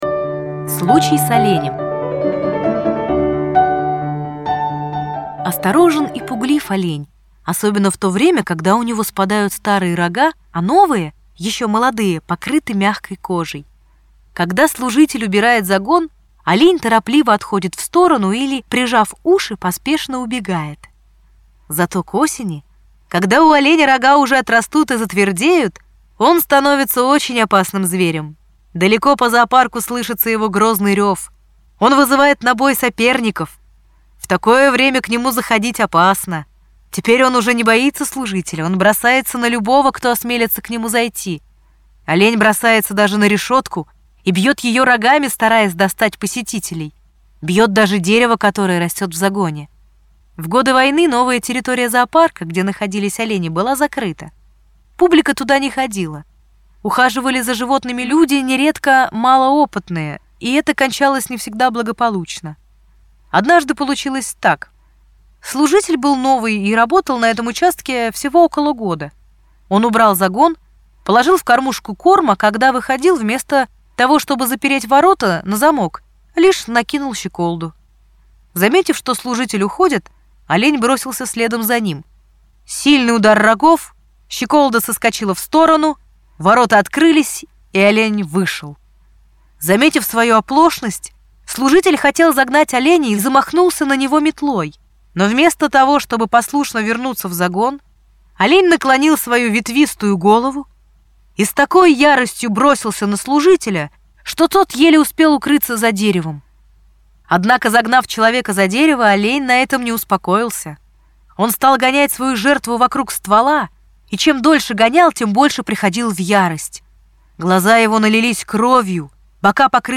Аудиорассказ «Случай с оленем»